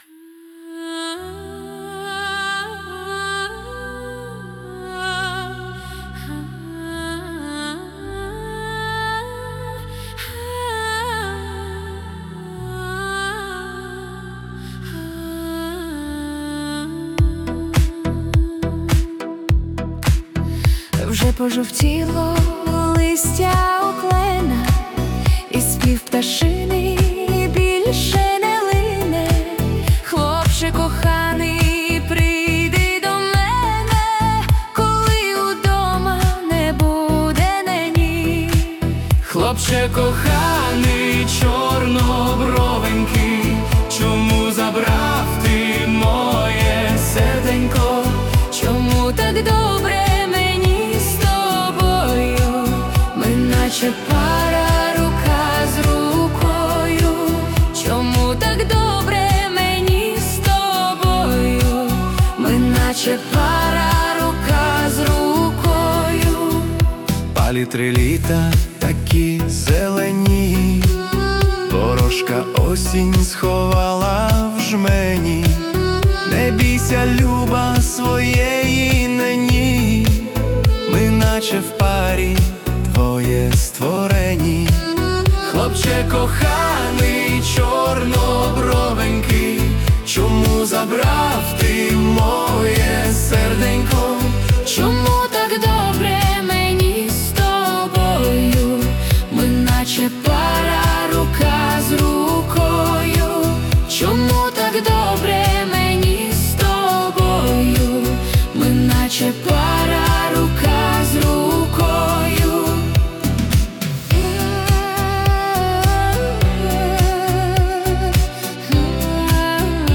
Чудові слова до гарної пісні з народним відтінком. Гарна мелодія, ритмічна, танцювальна. 12 12 Але так хочеться почути її протяжною, мелодійною.